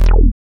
75.07 BASS.wav